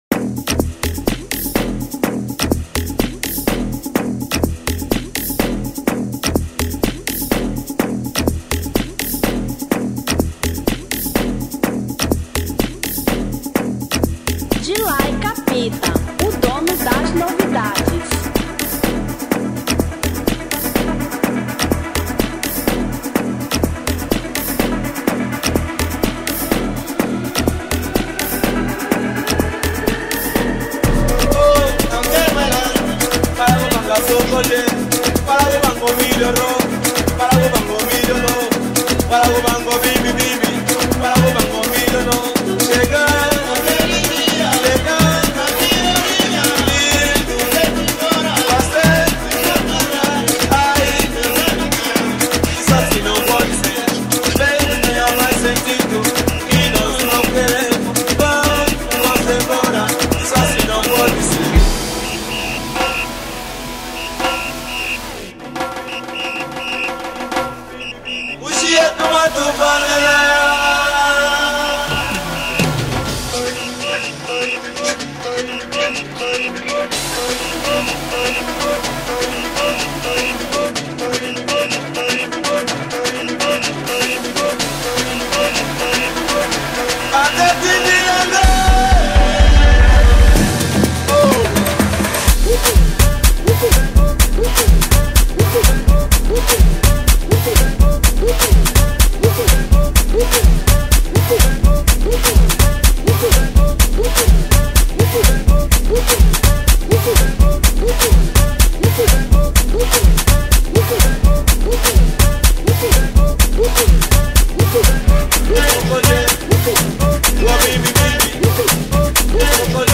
Afro House 2025